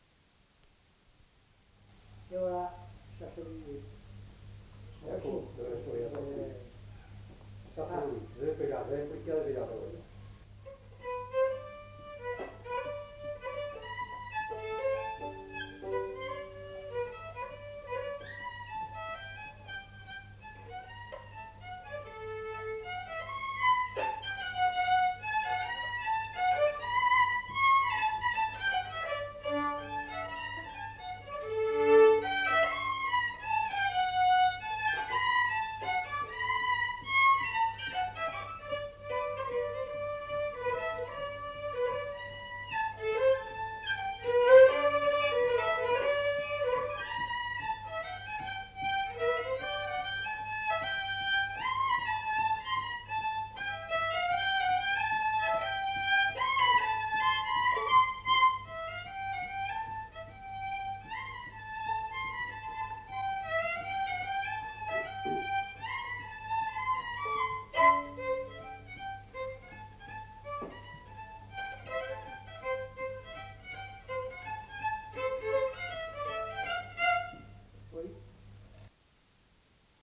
Aire culturelle : Lomagne
Genre : morceau instrumental
Instrument de musique : violon
Danse : mazurka
Notes consultables : Deux violons.